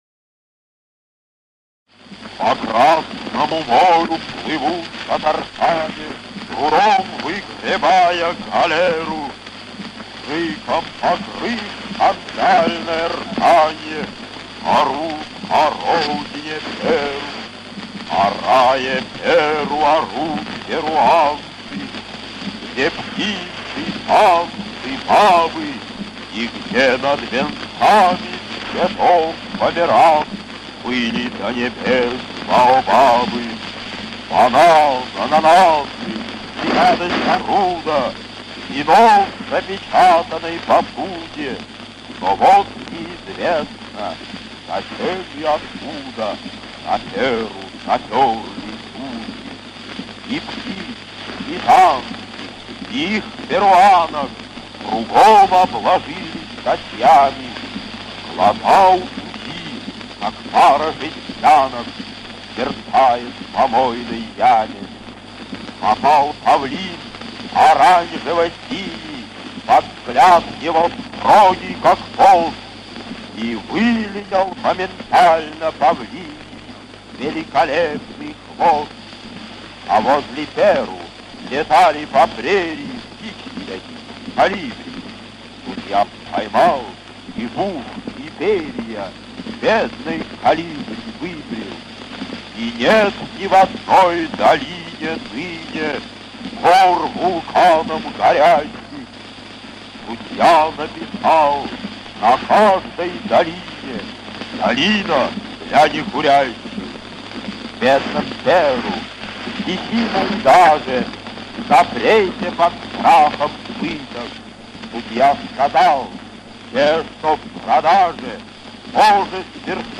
4. «Владимир Маяковский – Гимн судье (1920 г. читает автор)» /